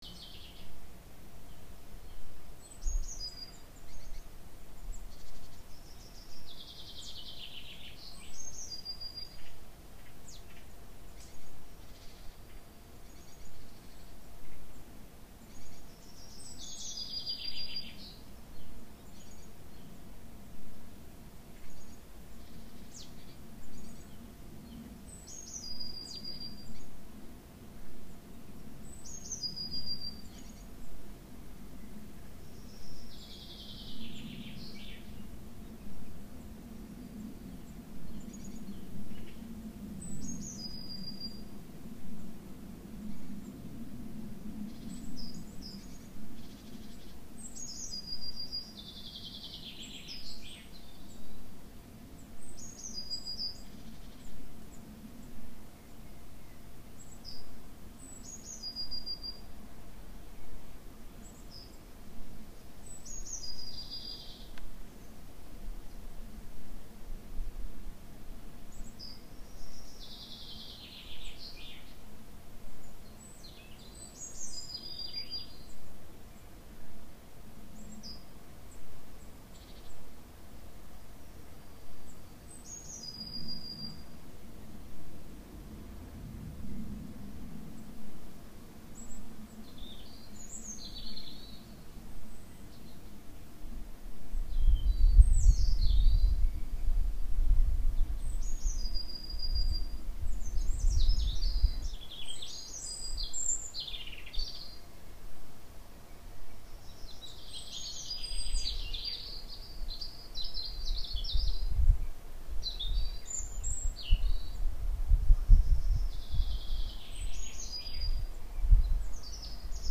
Son Ambiance_Mix Export_Mono.wav